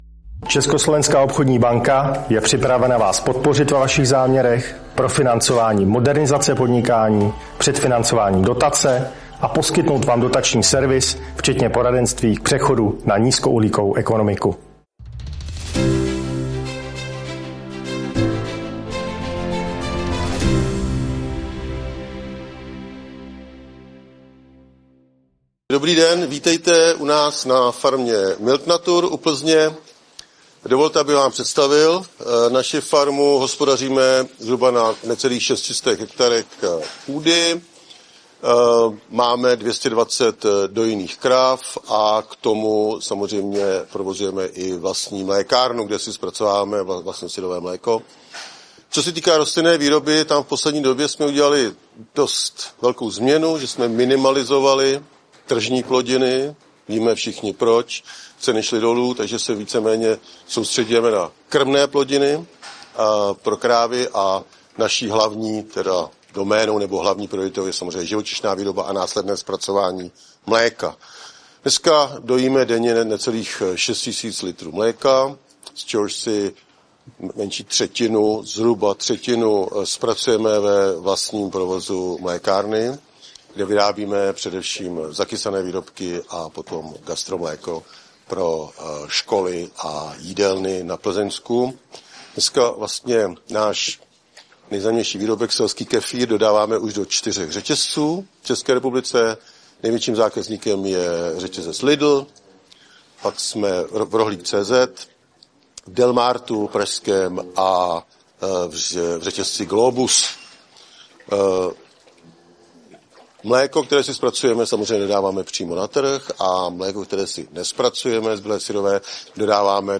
Pořad: TV reportáž